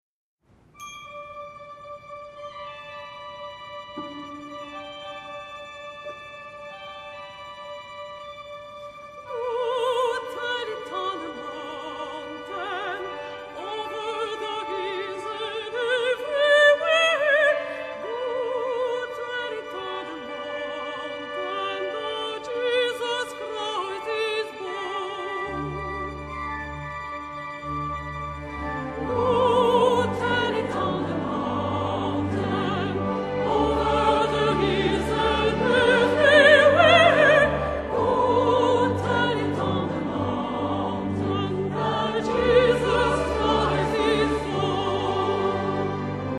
accompagnés de la soprano